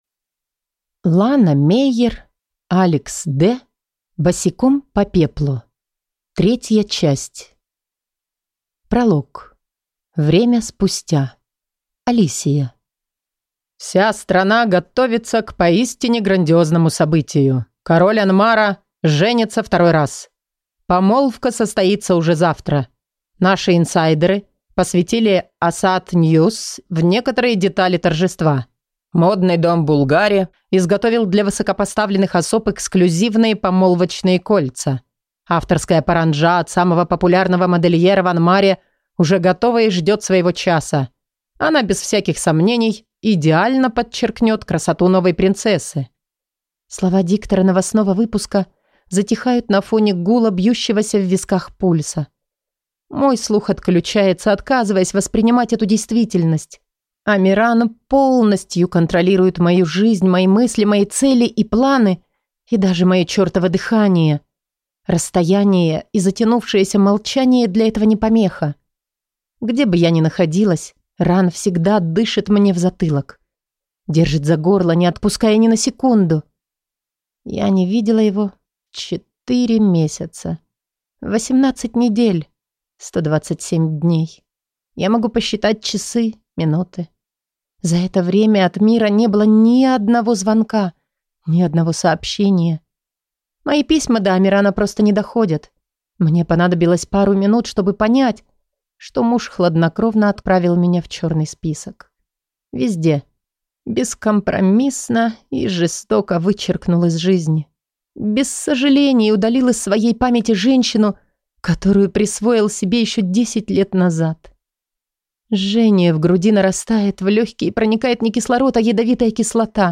Аудиокнига Босиком по пеплу. Книга 3 | Библиотека аудиокниг
Прослушать и бесплатно скачать фрагмент аудиокниги